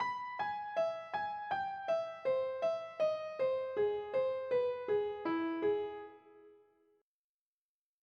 This example is a way of practicing minor thirds in major thirds.
practice example jazz augmented